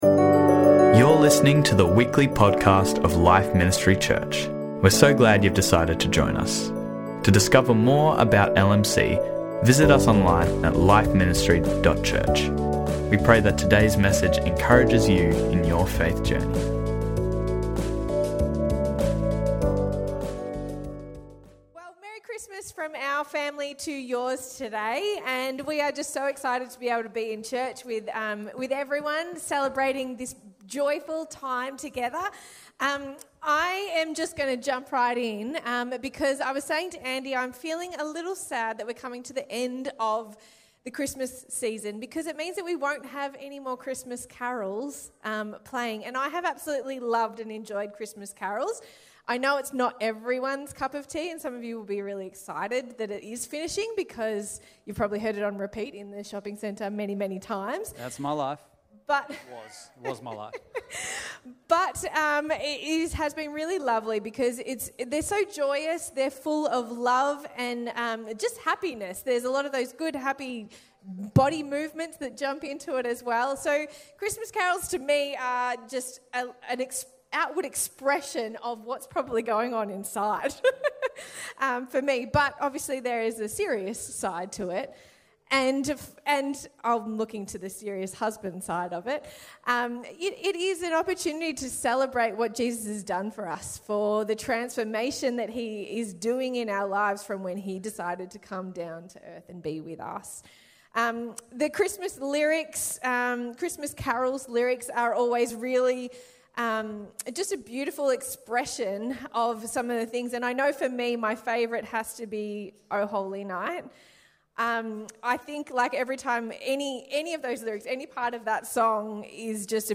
Christmas Day